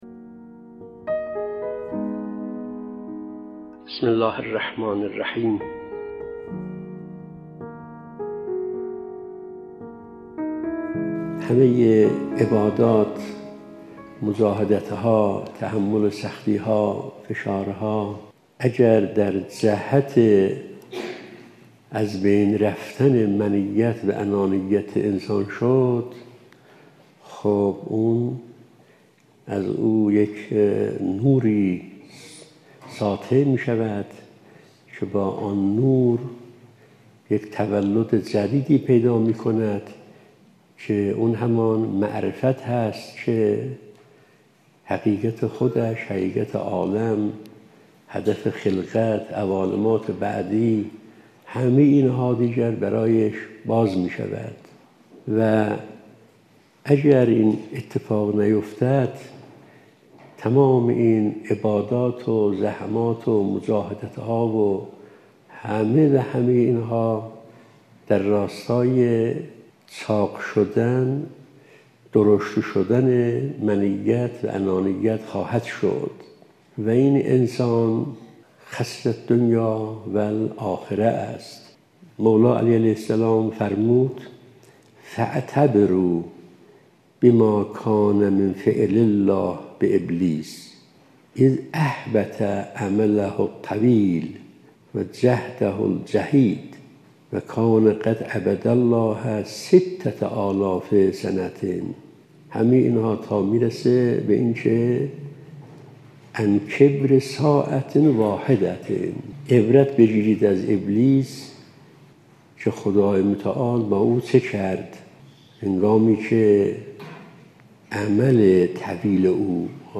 📍از جلسه اولین جمعه ماه قمری| ماه شعبان 🎙تربیت نفس| تبعات انانیت و منیت 📌شماره(۶) ⏳۹ دقیقه 🔗پیوند دریافت👇 🌐